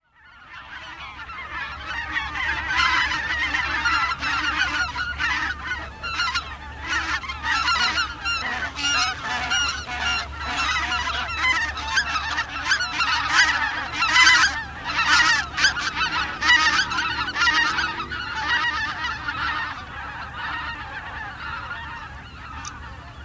kolgans
🔭 Wetenschappelijk: Anser albifrons
♪ contactroep 1
kolgans_roep.mp3